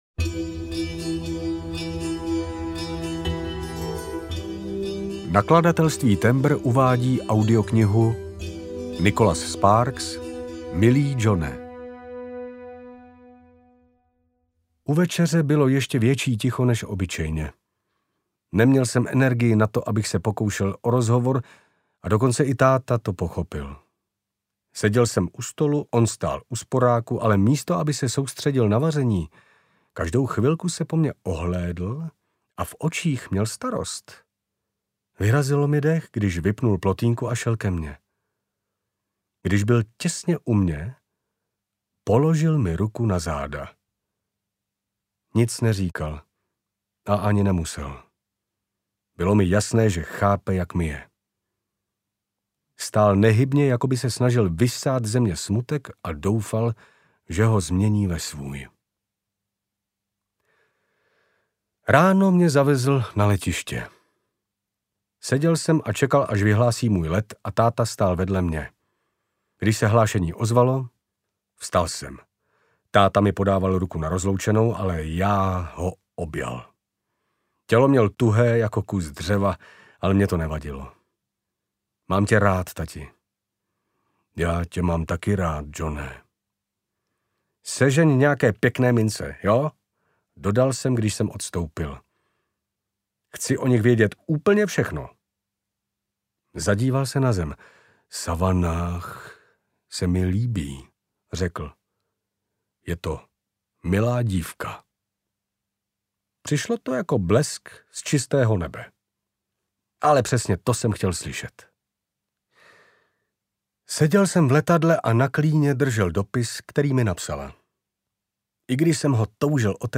Milý Johne audiokniha
Ukázka z knihy